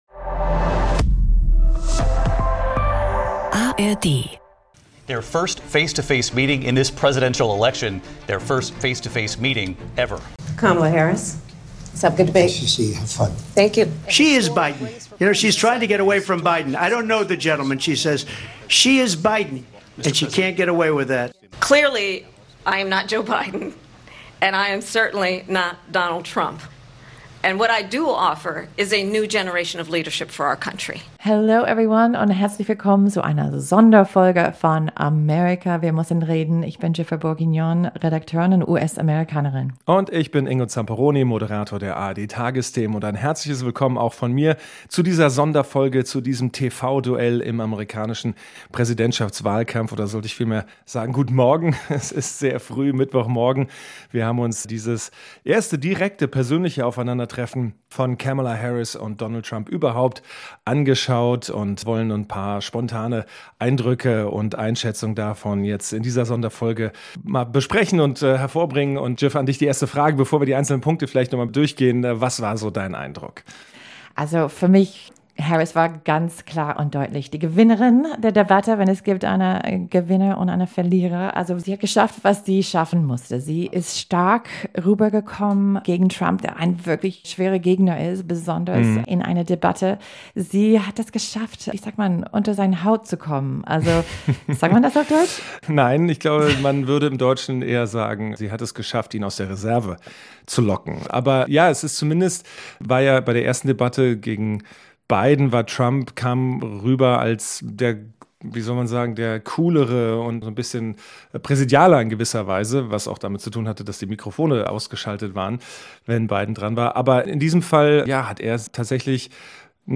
Um 6 Uhr morgens haben die beiden die Podcast-Folge aufgenommen.